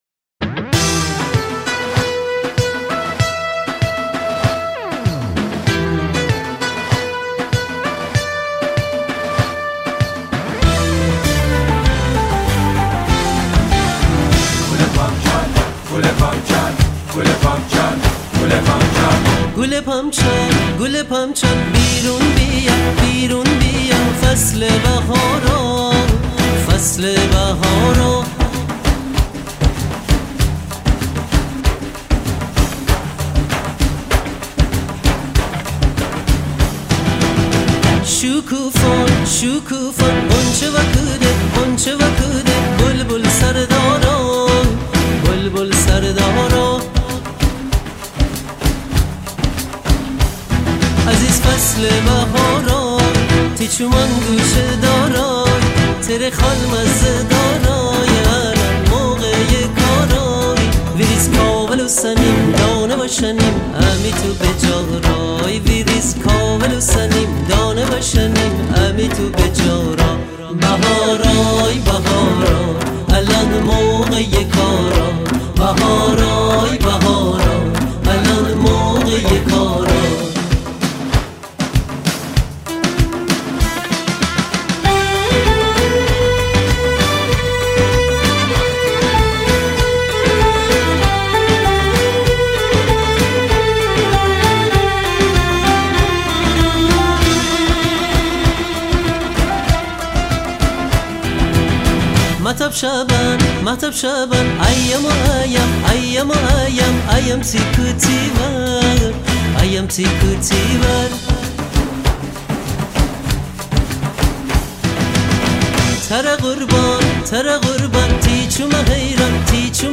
موسیقی فولکلور ایرانی